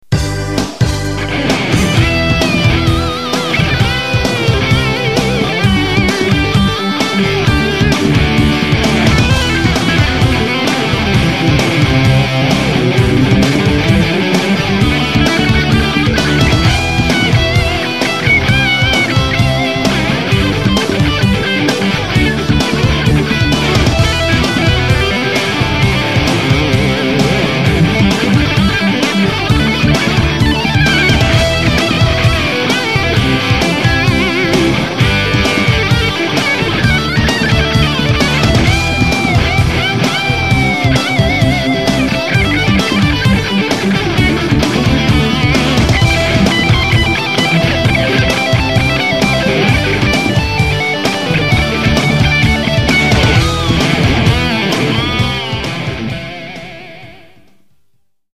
-     Instrument : Guitares lead et rythmique.
Le principe : tous les participants téléchargent une back-track commune, enregistrent un solo dessus et postent le mix sur le site.
Défi_1 : sur une back track de Kiko Loureiro (Angra).